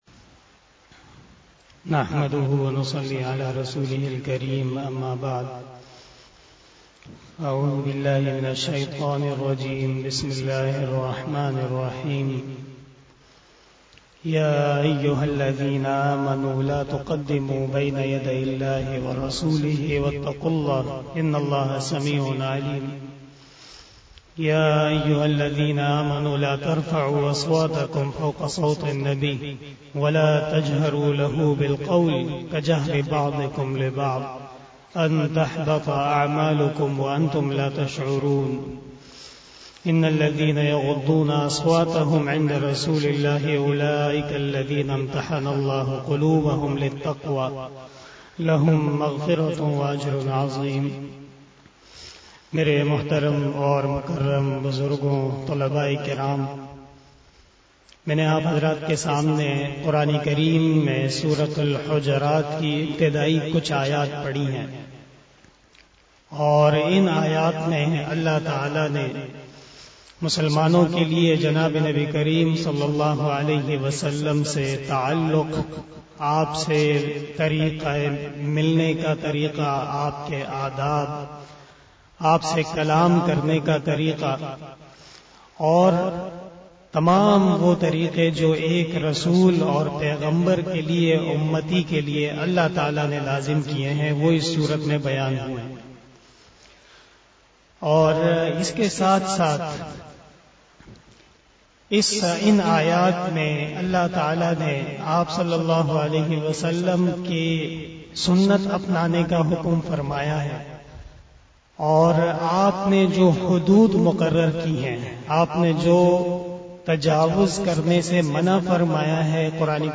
072 After Isha Namaz Bayan 18 October 2021 (12 Rabi ul Awwal 1443HJ) Monday